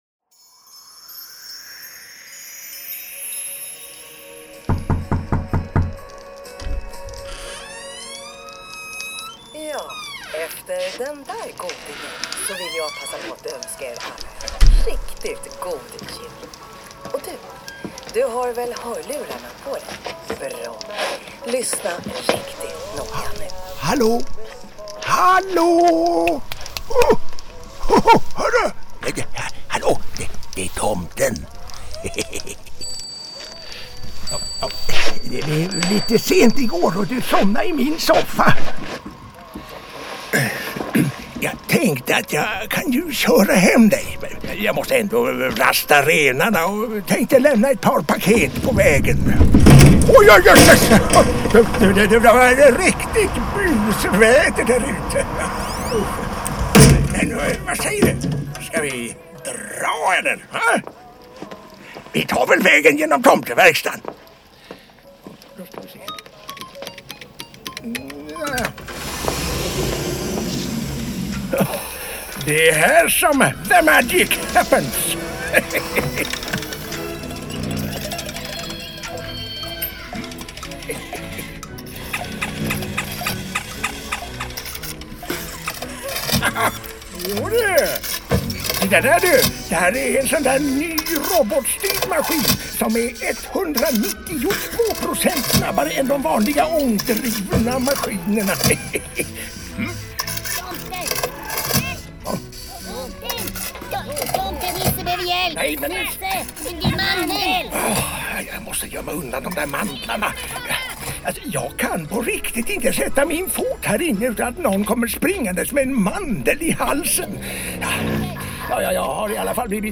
Men innan du klickar på ”Lös in här” – ta på dig lurar och följ med in i en magisk julsaga med 3D-ljud.